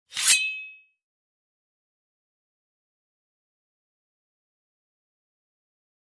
Sword Shing: Small Metal Dagger Unsheathed.